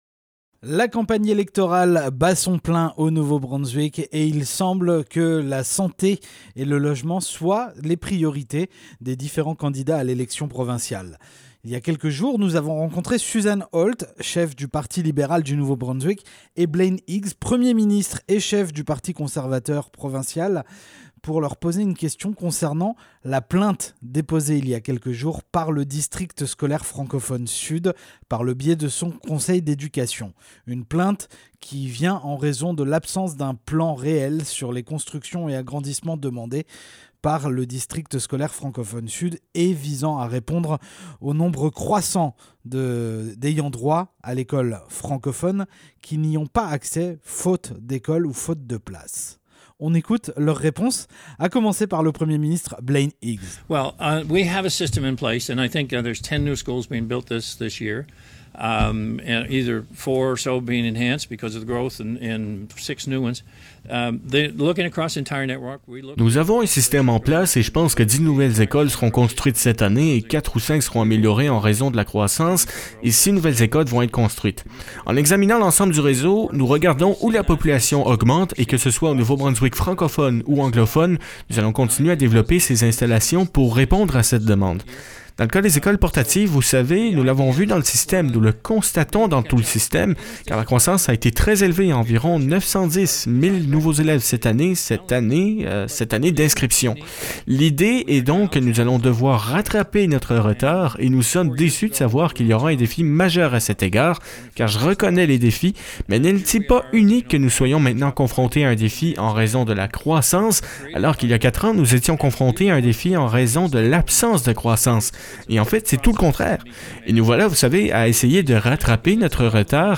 Susan Holt, cheffe du parti libéral du Nouveau-Brunswick et Blaine Higgs, premier ministre et chef du parti progressiste-conservateur provincial, nous répondent.